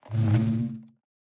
Minecraft Version Minecraft Version 25w18a Latest Release | Latest Snapshot 25w18a / assets / minecraft / sounds / block / beehive / work1.ogg Compare With Compare With Latest Release | Latest Snapshot